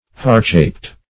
Search Result for " heartshaped" : The Collaborative International Dictionary of English v.0.48: heartshaped \heart"shaped`\, heart-shaped \heart"-shaped`\(h[aum]rt"sh[=a]pt`), a. Having the shape of a heart; cordate; -- of a leaf shape.